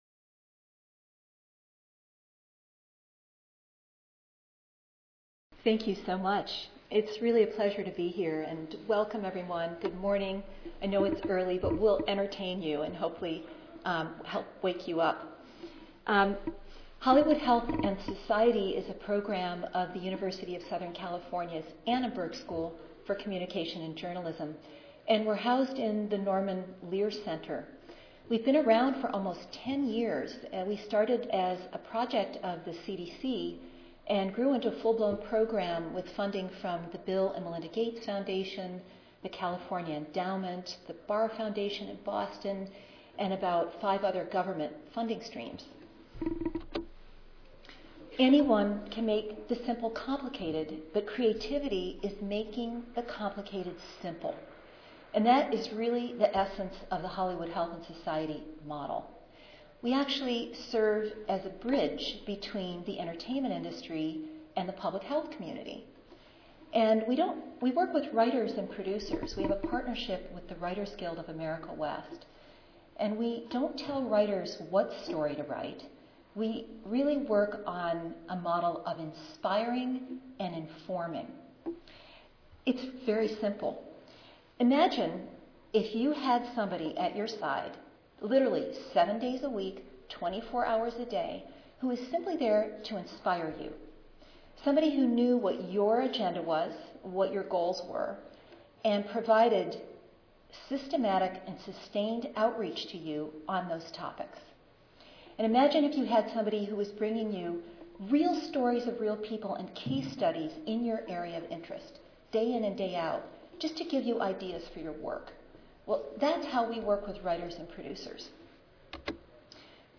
Join the Ad Council for a thought-provoking panel that will include senior level executives discussing the linkages between media and tackling critical social issues including how the Kaiser Family Foundation successfully embedded health messages in partnership with Grey�s Anatomy and how MTV�s 16 and Pregnant is changing the social norms around teen pregnancy.